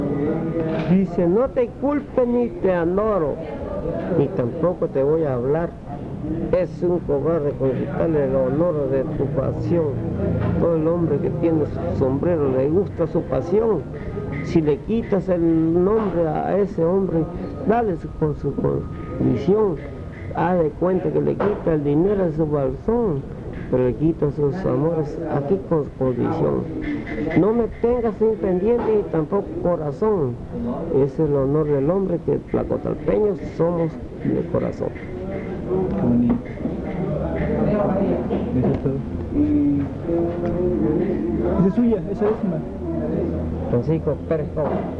Fiesta de La Candelaria: investigación previa